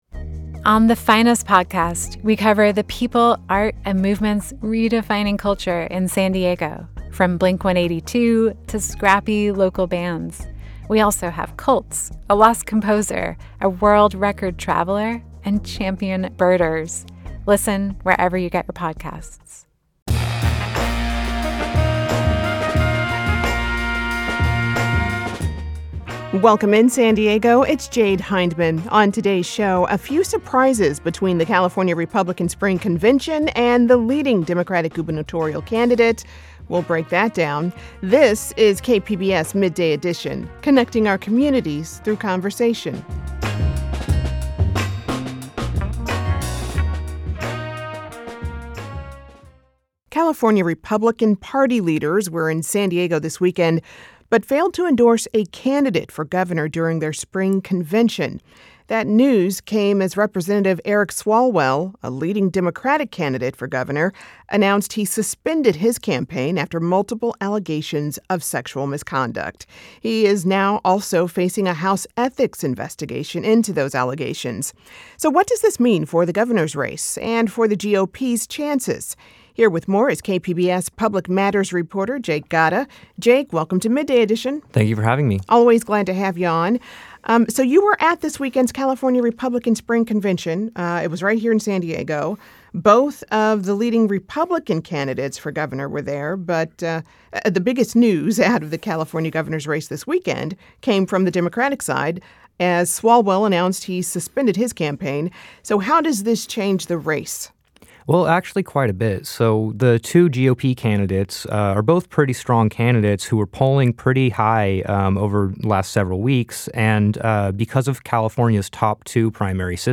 Editor's note: This interview was broadcasted several hours before Rep. Eric Swalwell of California announced he plans to resign from Congress following multiple sexual assault allegations.